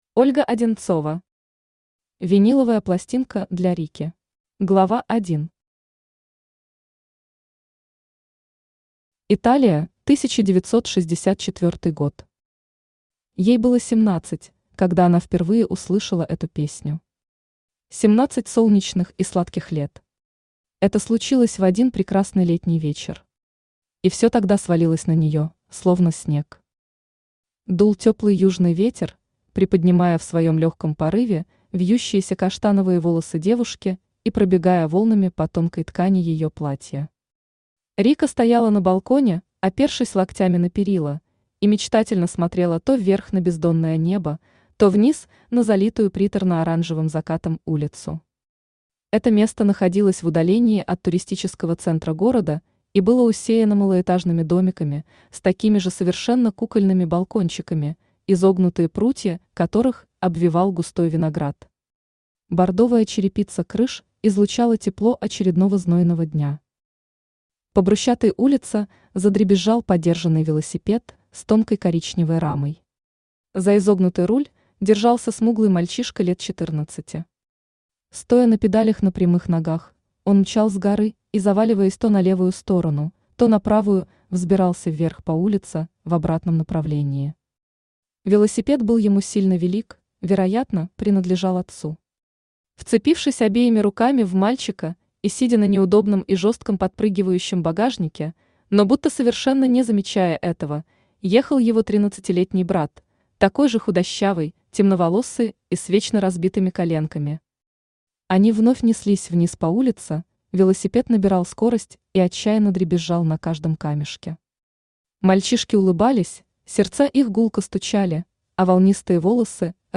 Аудиокнига Виниловая пластинка для Рикки | Библиотека аудиокниг
Aудиокнига Виниловая пластинка для Рикки Автор Ольга Одинцова Читает аудиокнигу Авточтец ЛитРес.